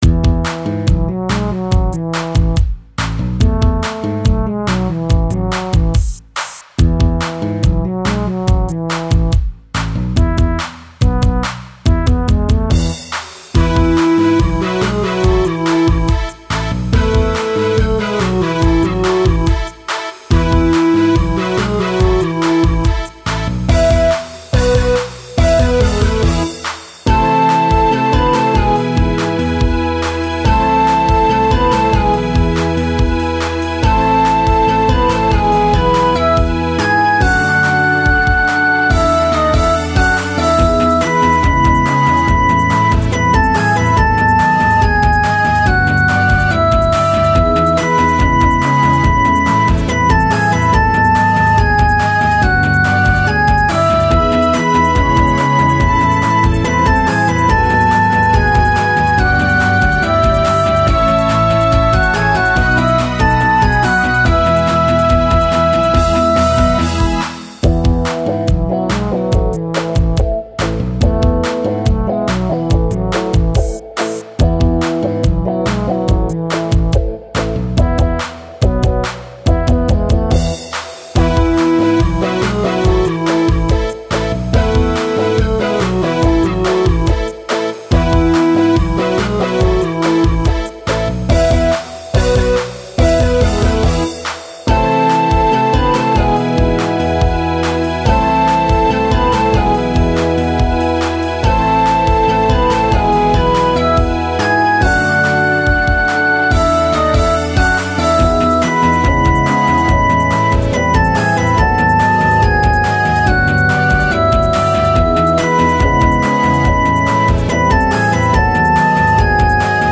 楽式はヴァース×２、プレコーラス、コーラスというよくある構成。
二週目はギタートラックをひとつ差し込んでるんだけど、別になくても成り立つっぽいな。
ヴァースはⅠ－Ⅳ進行で、パワーコードで構成。
全体で二オクターブ近くの音域になってしまった。